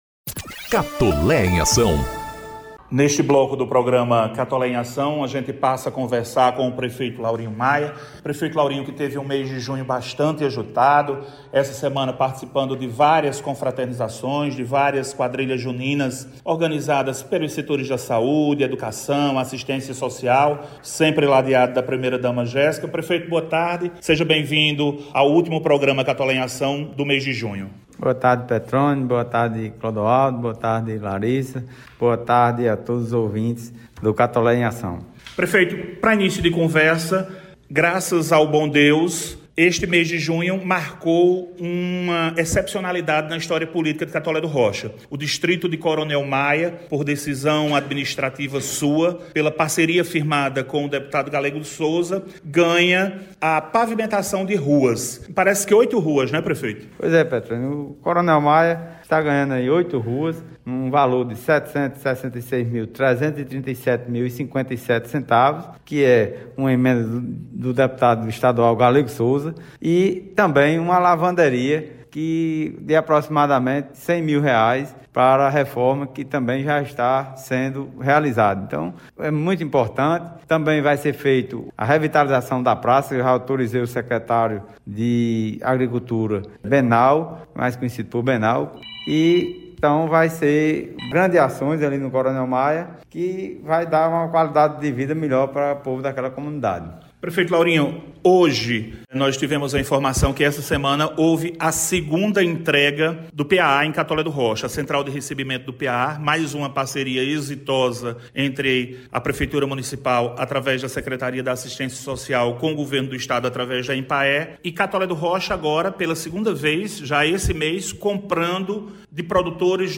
CATOLÉ EM AÇÃO: Programa completa 01 ano e Prefeito Laurinho destaca ações. Ouça a entrevista!
O Programa Institucional ‘Catolé em Ação’ completou, na última sexta-feira (24/06), o primeiro ano de exibição e contou com as participações do prefeito Laurinho Maia, secretários municipais, auxiliares da gestão e vereadores.